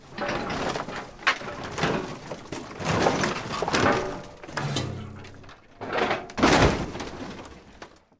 garbage_hard_0.ogg